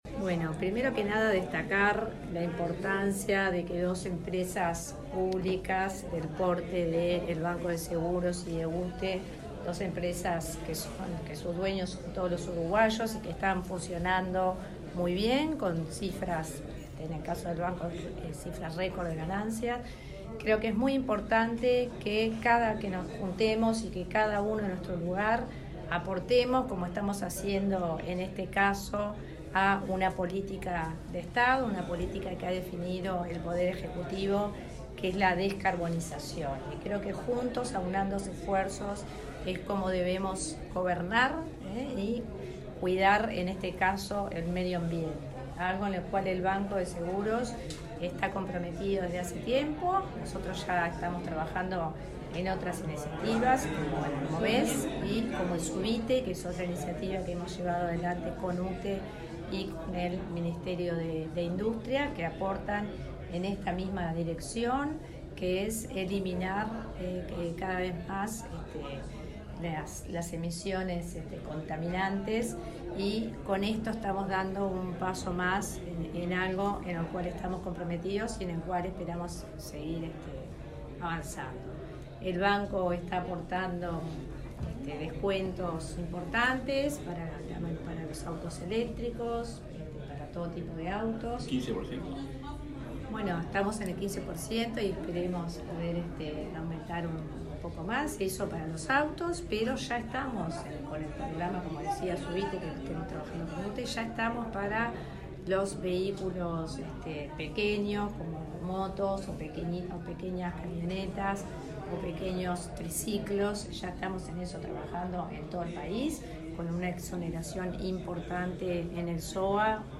Declaraciones de la vicepresidenta del BSE, Silvana Olivera
Declaraciones de la vicepresidenta del BSE, Silvana Olivera 19/12/2022 Compartir Facebook X Copiar enlace WhatsApp LinkedIn Este lunes 19, la UTE y el Banco de Seguros del Estado (BSE) firmaron un acuerdo de complementación comercial. Luego la vicepresidenta del organismo asegurador, Silvana Olivera, dialogó con la prensa.